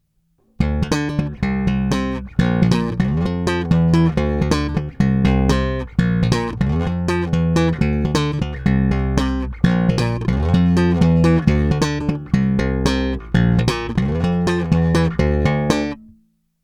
A to i se zkreslením a zvuk je dokonce výtečný i při slapu.
Není-li uvedeno jinak, následující nahrávky jsou nahrány rovnou do zvukovky a dále jen normalizovány.
Slap